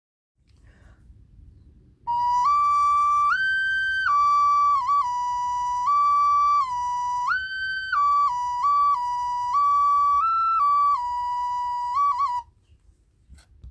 サンダルウッドオカリナ
マダガスカルの職人が作る美しい響きのオカリナ澄み切った音色が心癒されます。形状により音程はそれぞれ異なります。
素材： サンダルウッド